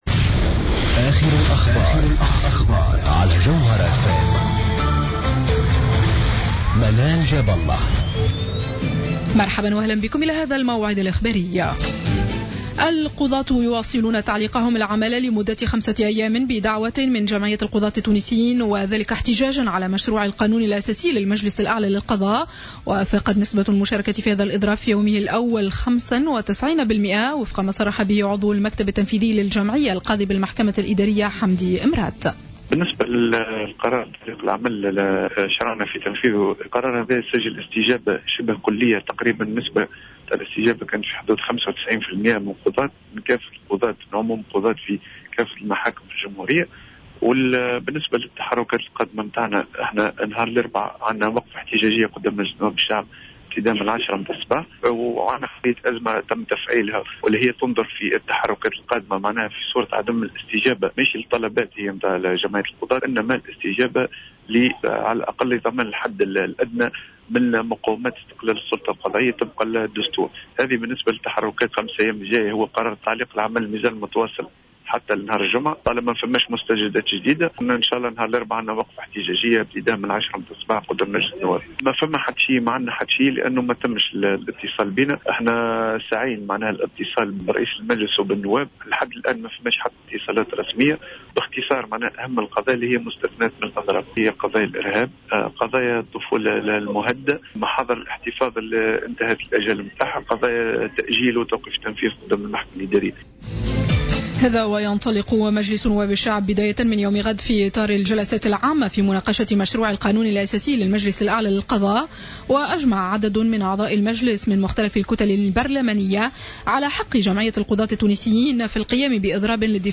نشرة أخبار السابعة مساء ليوم الاثنين 11 ماي 2015